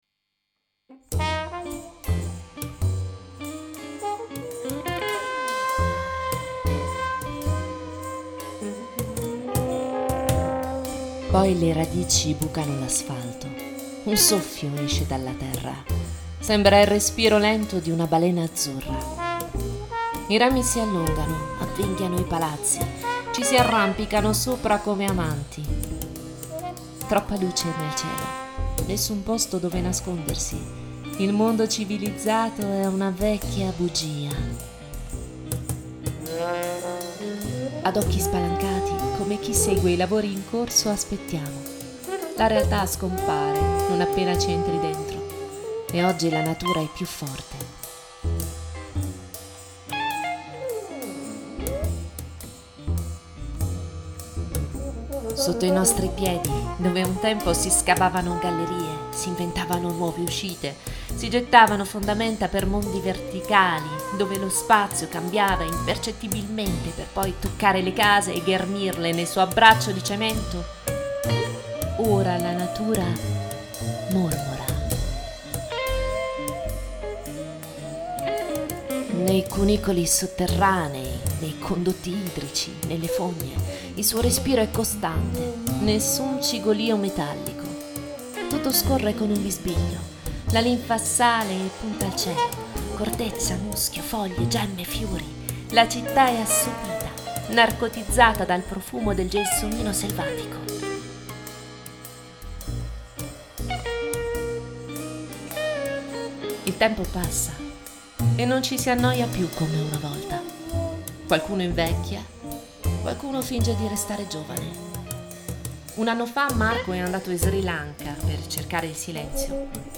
Audio libri
Un quartetto di polistrumentisti che prediligono suoni acustici e analogici, e che progettano il “mood” del brano, il clima, per poi affidarsi completamente alla fantasia e alle capacità improvvisative di ciascuno.
basso elettrico